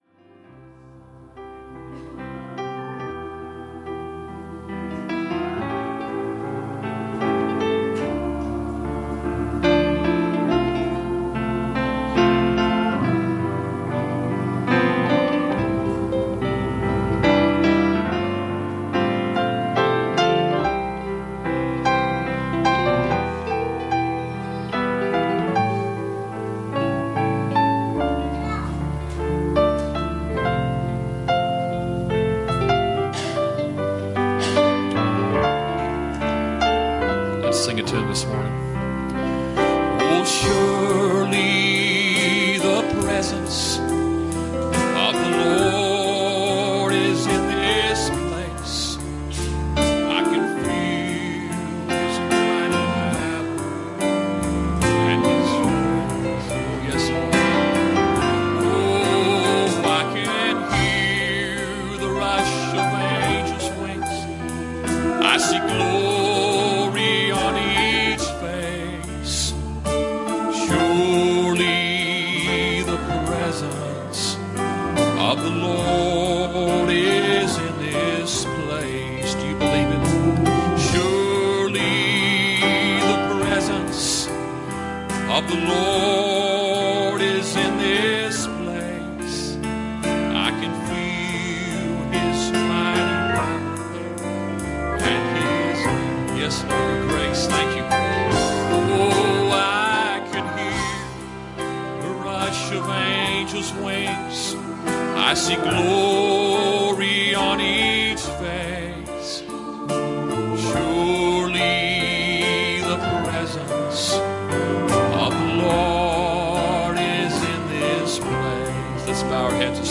Passage: 1 John 4:4 Service Type: Sunday Morning